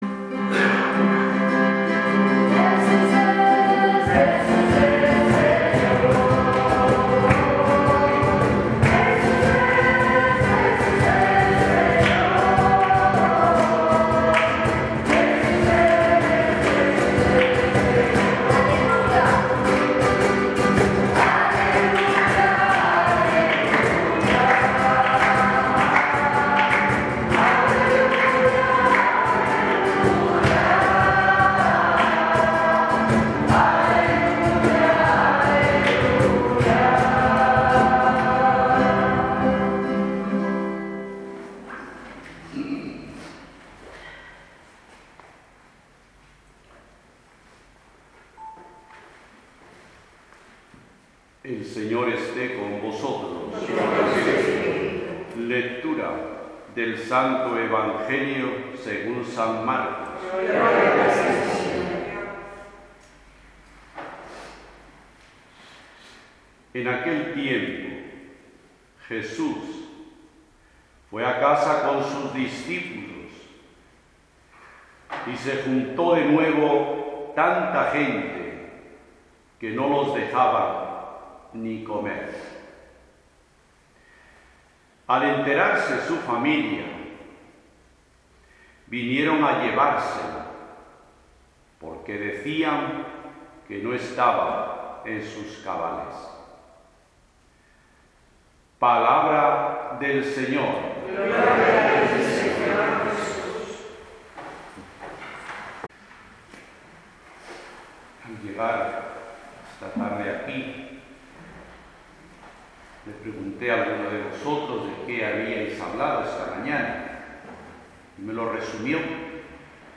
Homilia.mp3